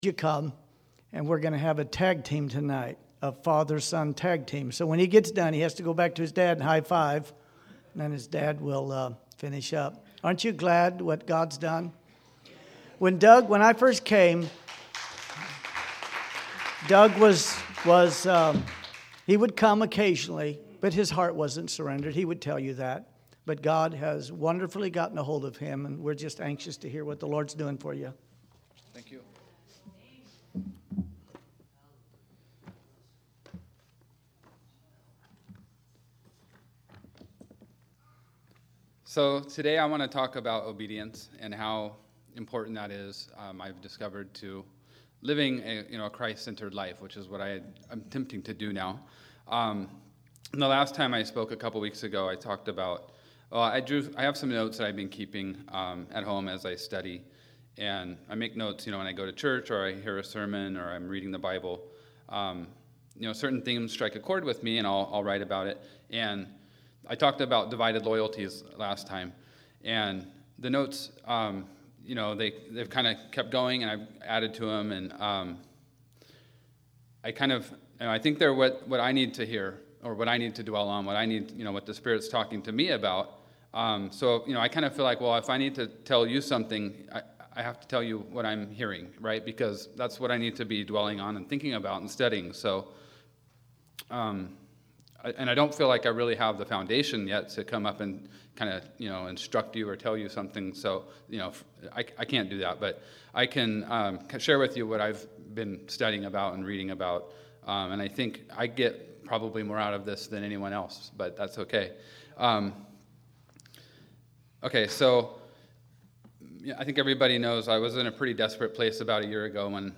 Sermon Categories
Evening Sermons